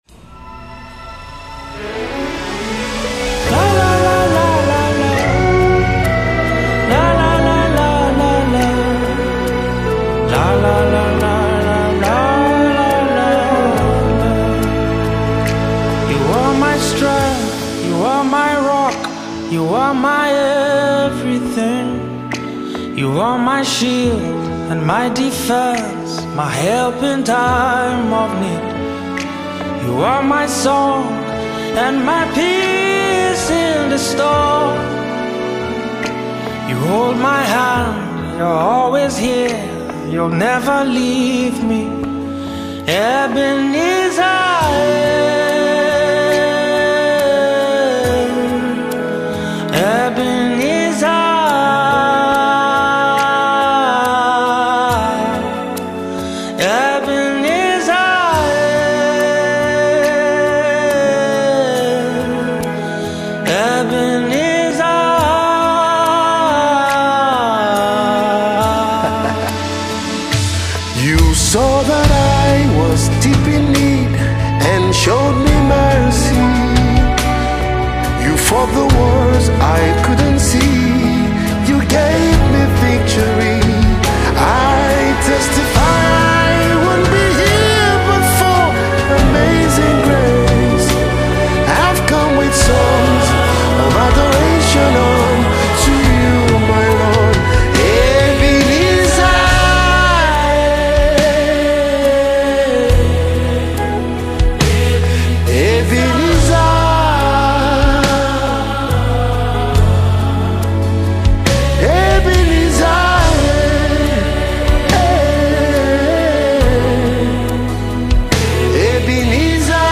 Renowned Nigerian gospel artist and minister